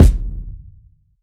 Kick MadFlavor 1.wav